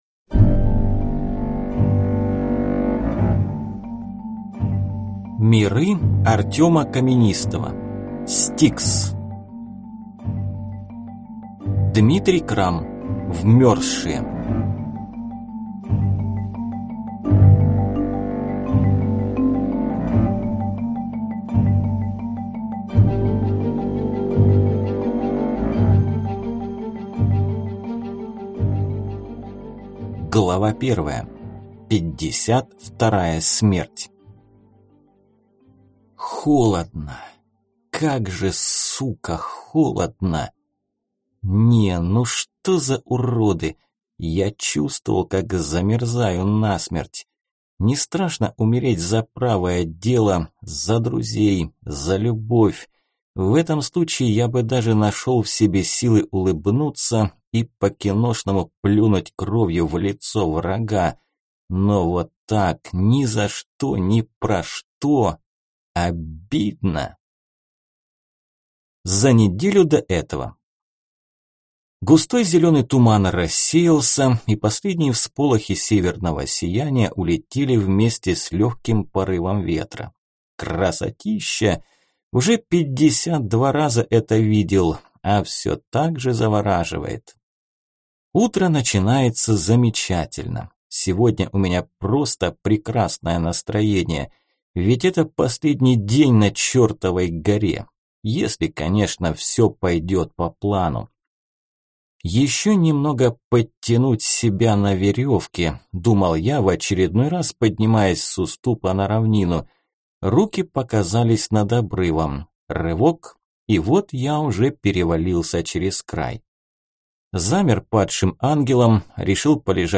Аудиокнига S-T-I-K-S. Вмерзшие | Библиотека аудиокниг